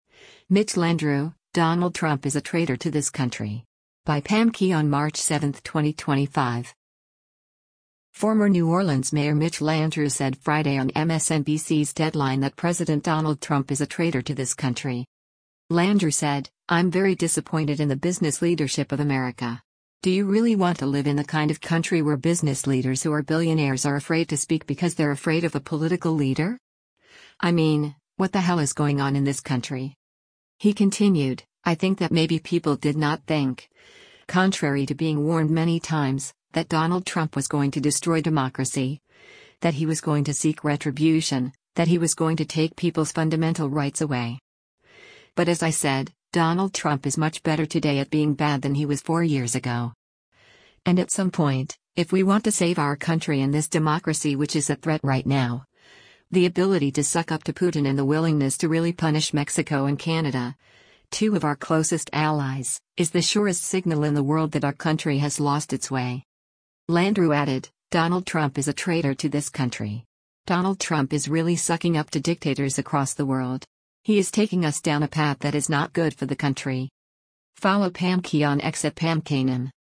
Former New Orleans Mayor Mitch Landrieu said Friday on MSNBC’s “Deadline” that President Donald Trump is a “traitor to this country.”